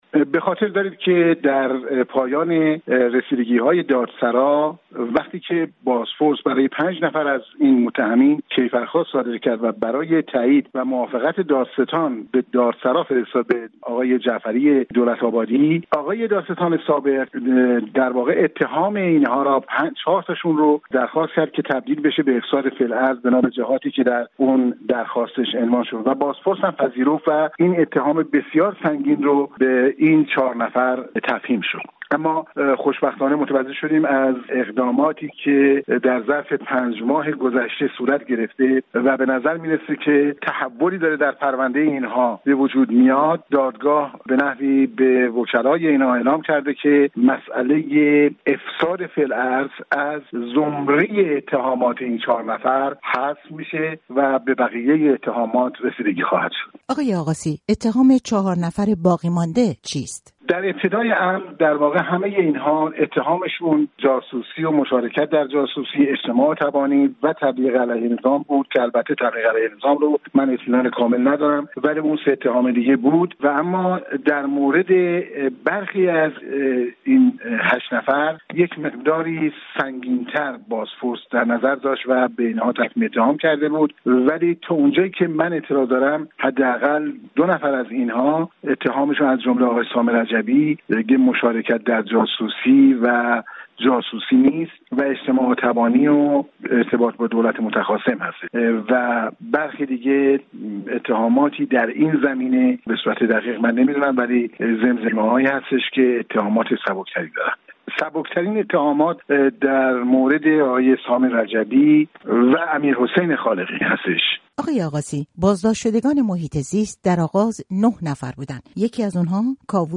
خبرها و گزارش‌ها
برنامه‌های رادیویی